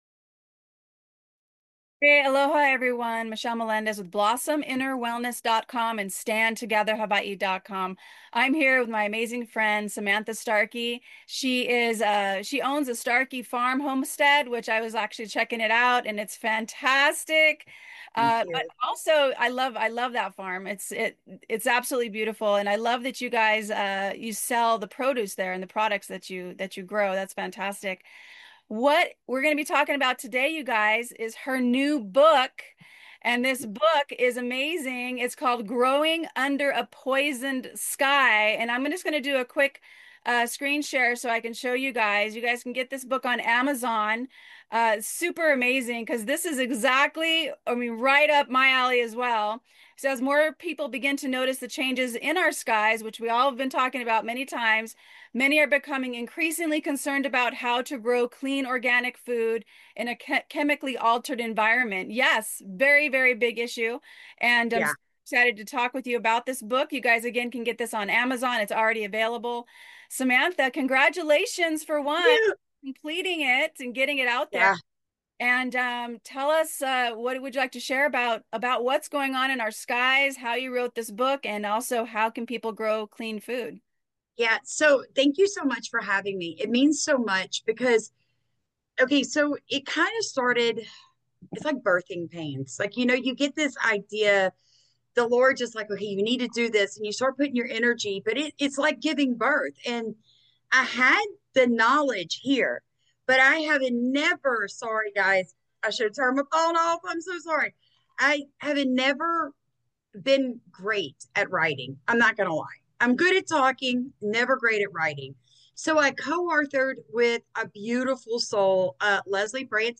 Growing Food Under A Poisoned Sky! (Interview